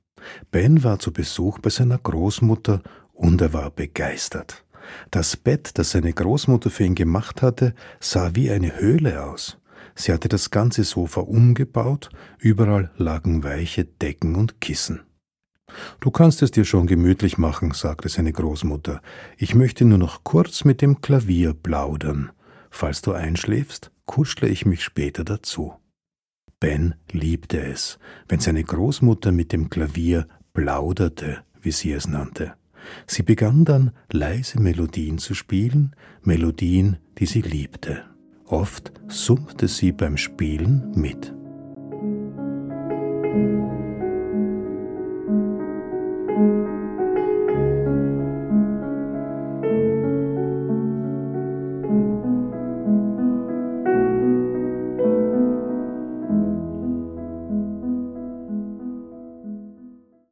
Die Mondscheinsonate (Mein erstes Musikbilderbuch mit CD und zum Streamen, Bd.) Die Klaviersonate